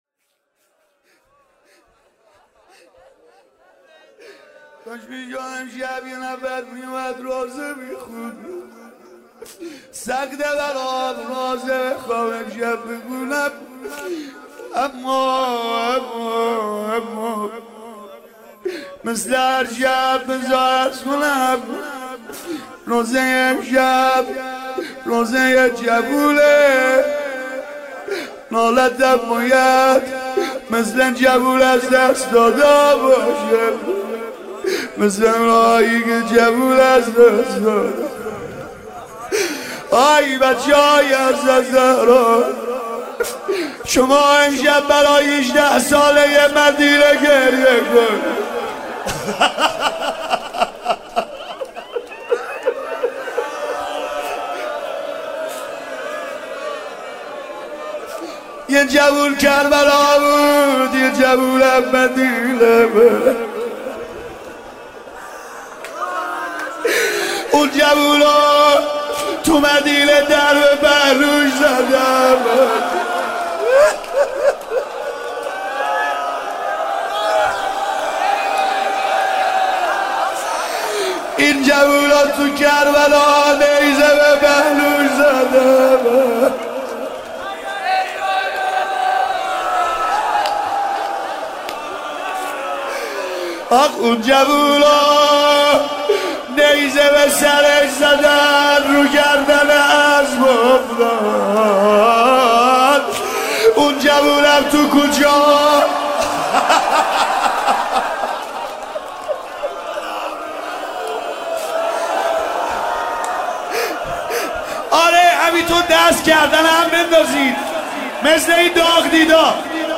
شب هشتم محرم
مداح : سید رضا نریمانی
مناجات